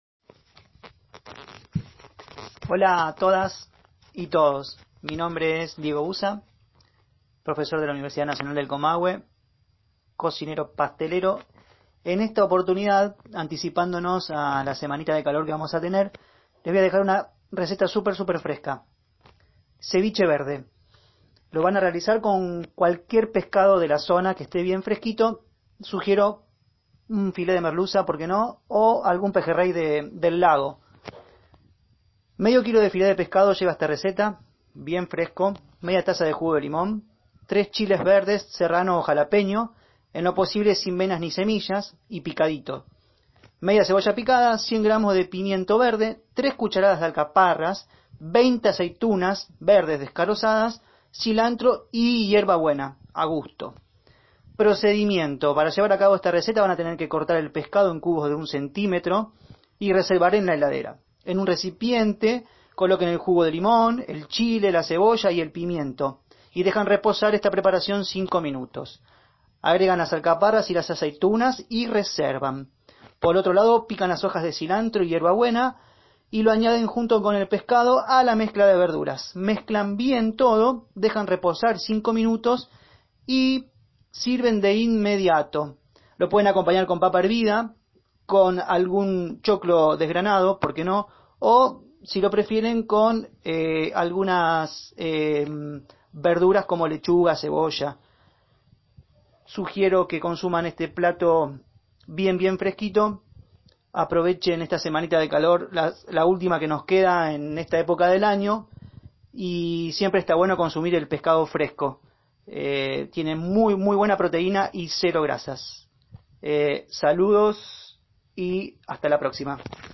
Audio receta de cómo preparar ceviche verde